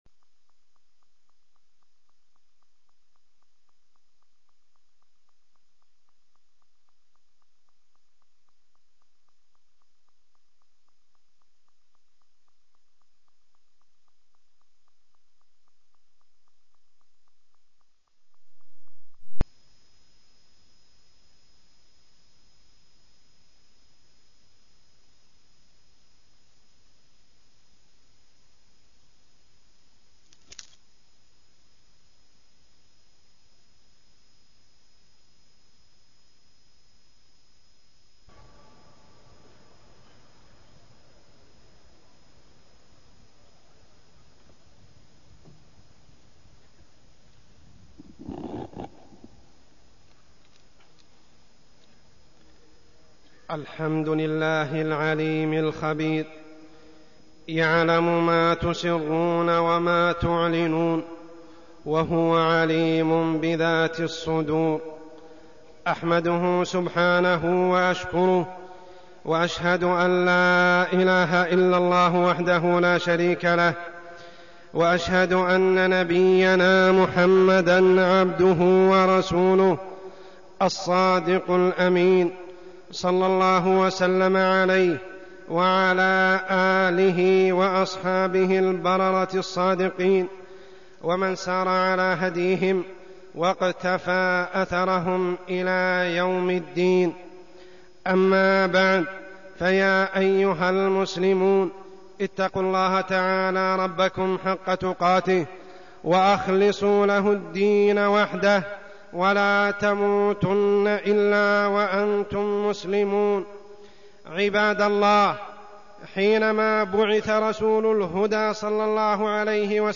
تاريخ النشر ٢٦ ربيع الثاني ١٤١٨ هـ المكان: المسجد الحرام الشيخ: عمر السبيل عمر السبيل مكائد المنافقين